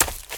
STEPS Leaves, Run 03.wav